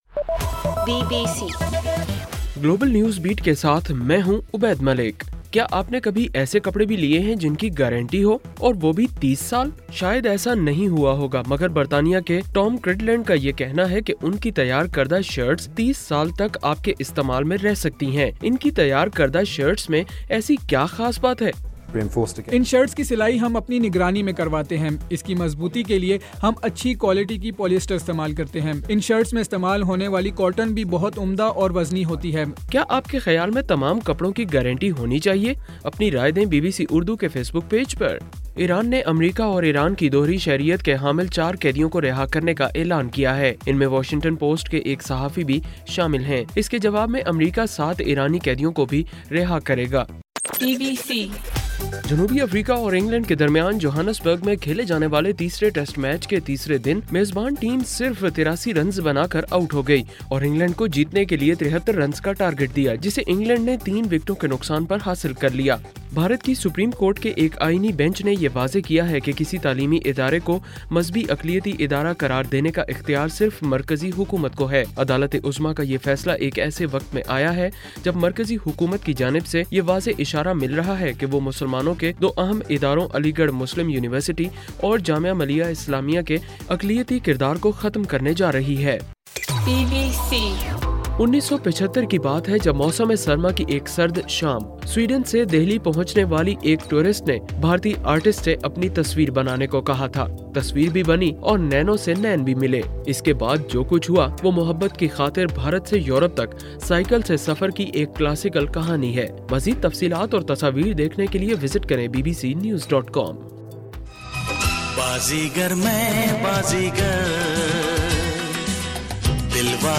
جنوری 16: رات 12 بجے کا گلوبل نیوز بیٹ بُلیٹن